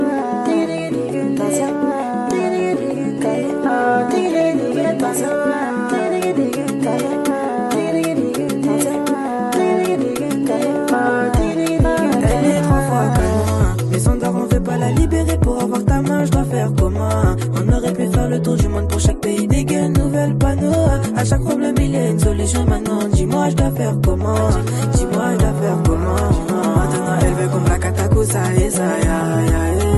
Жанр: Африканская музыка
# Afro-Beat